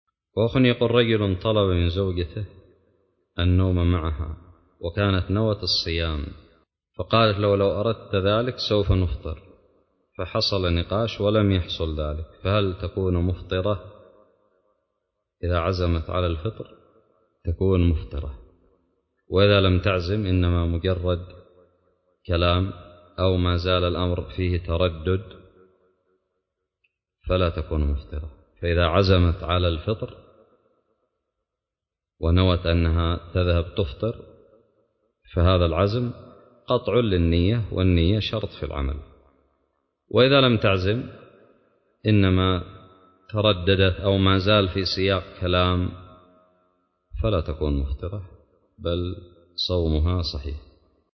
:العنوان فتاوى الصيام :التصنيف 1444-10-12 :تاريخ النشر 23 :عدد الزيارات البحث المؤلفات المقالات الفوائد الصوتيات الفتاوى الدروس الرئيسية رجل طلب من زوجته النوم معه وقد نوت الصوم فقالت إن أردت ذلك سوف نفطر ...؟